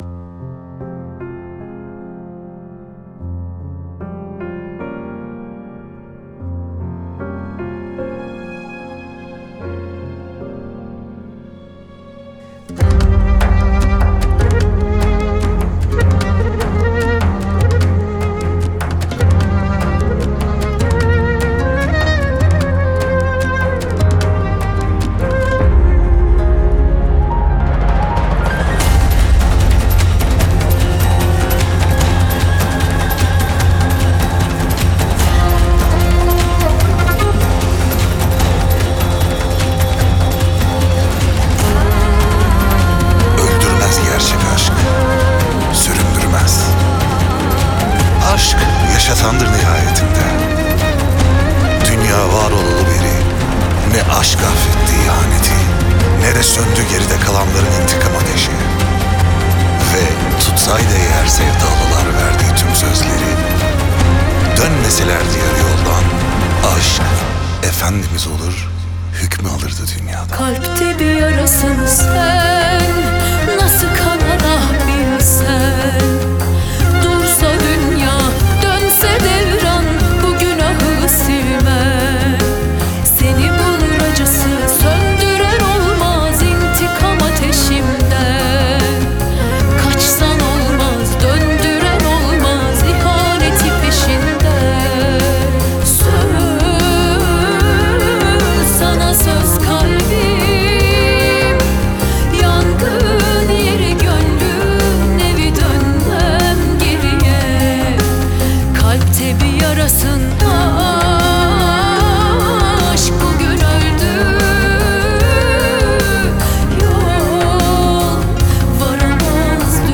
dizi müzikleri
duygusal hüzünlü heyecan şarkı.